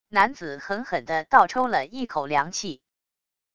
男子狠狠的倒抽了一口凉气wav音频